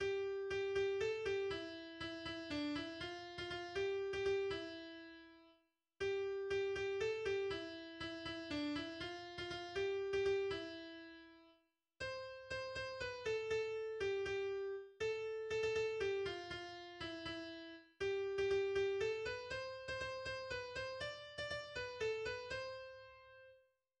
Lied
Melodie